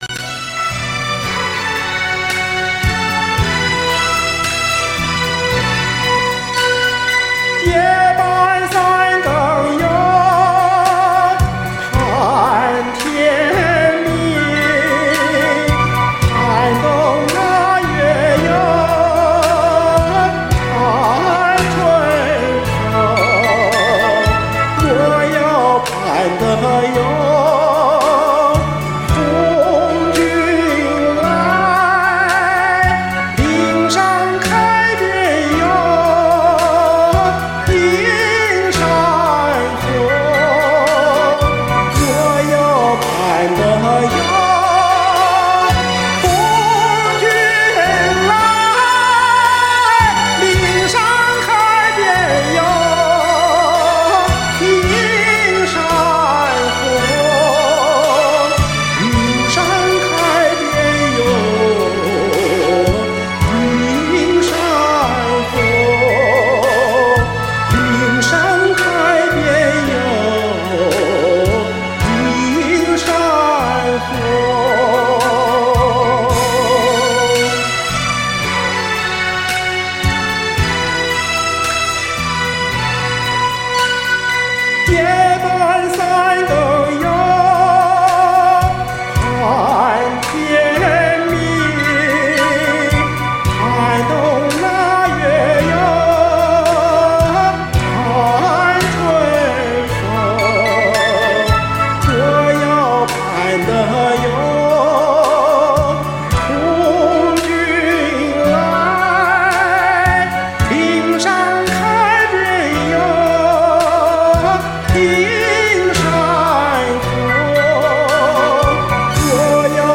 高音很舒展有张力！
铿锵有力，韵味十足！
特别敞亮大气有穿透力， 唱得云开雾散， 山花烂漫！
穿云裂石，好声音！